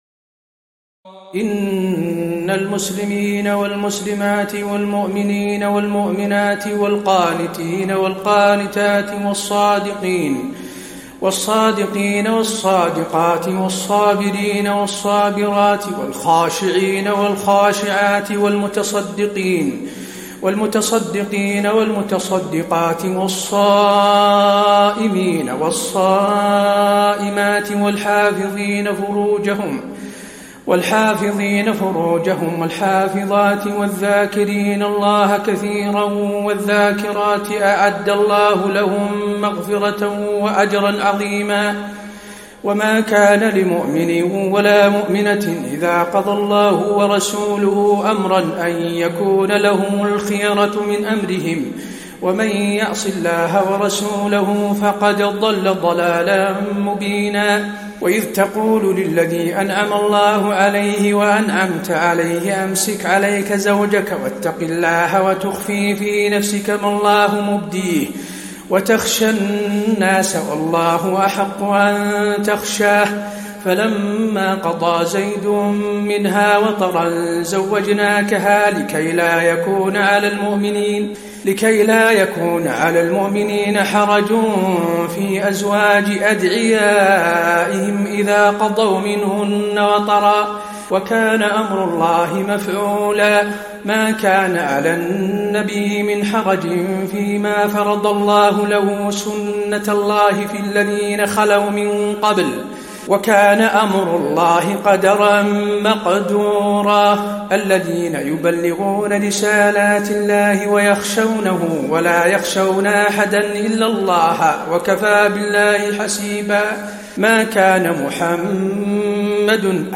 تراويح ليلة 21 رمضان 1435هـ من سور الأحزاب (35-73) وسبأ (1-23) Taraweeh 21 st night Ramadan 1435H from Surah Al-Ahzaab and Saba > تراويح الحرم النبوي عام 1435 🕌 > التراويح - تلاوات الحرمين